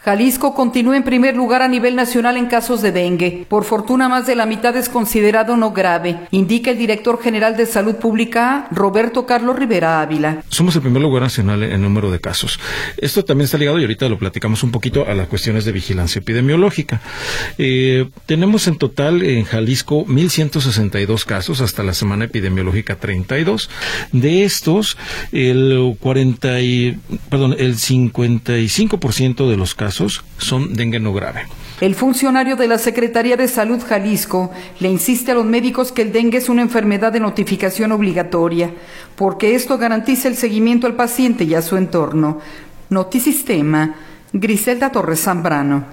Jalisco continúa en primer lugar a nivel nacional en casos de dengue, por fortuna más de la mitad es considerado no grave, indica el director general de salud pública, Roberto Carlos Rivera Ávila.